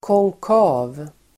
Ladda ner uttalet
Uttal: [kångk'a:v]